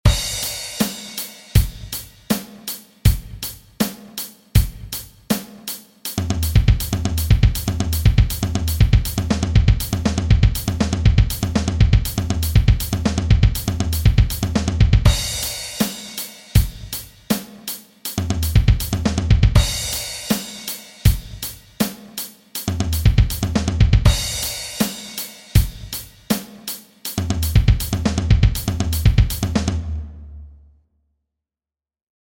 Ich zeige dir Schritt für Schritt einen einfachen Rhythmus mit einem optisch interessanten Fill-In.
Interessantes Drum Fill.mp3
interessantes_drum_fill.mp3